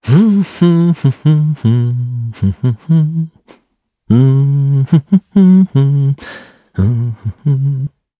Esker-Vox_Hum_jp.wav